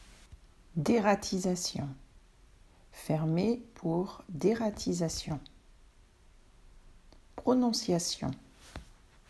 3. Dératisation: Rattenbekämpfung (deerattisassjõ)